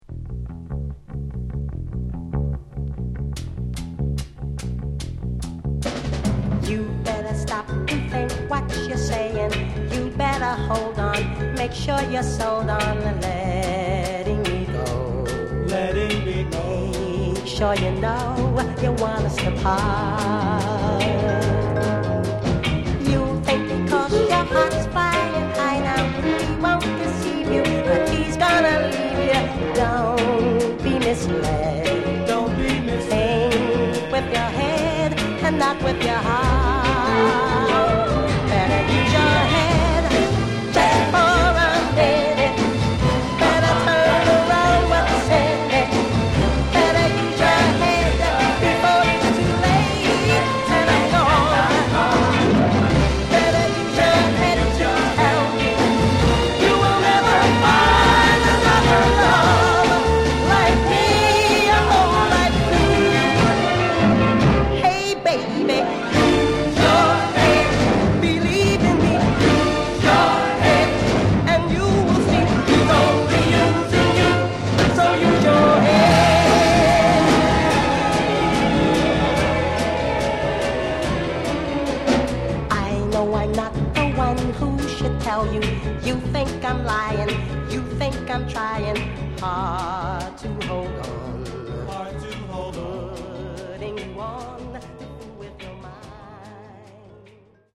Genre: Other Northern Soul